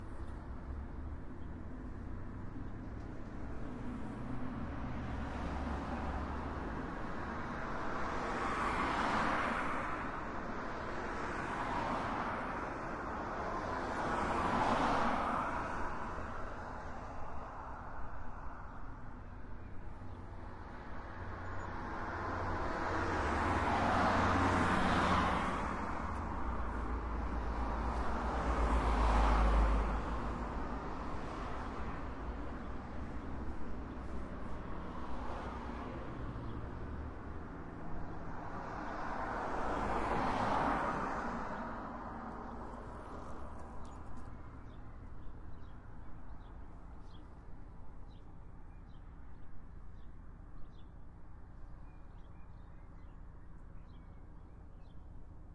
丹麦交通 " 卡车、汽车、自行车都能通过
描述：卡车，汽车，自行车通过。天气干燥，春天的时候。
Tag: 交通运输 传递 公路 高速公路 免费 汽车 高速公路